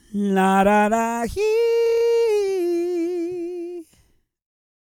E-CROON 209.wav